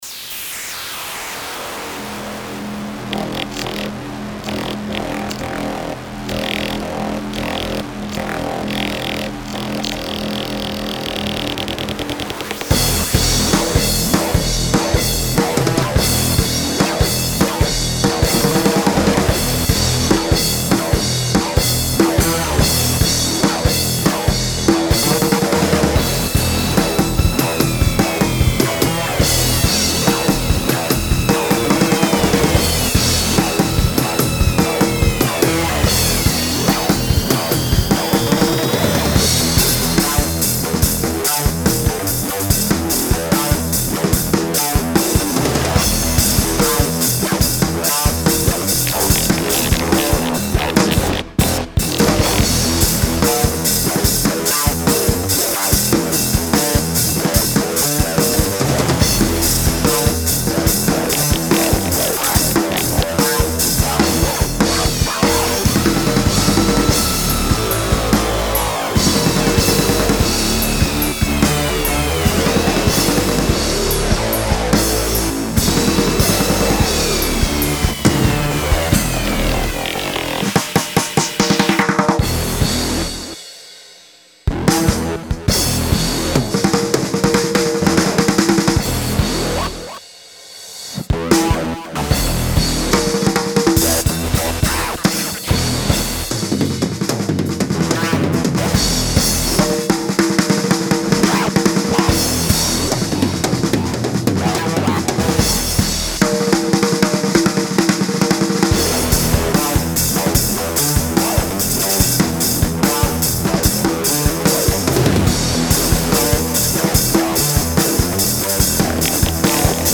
Electric
Studioproduktion | Dortmund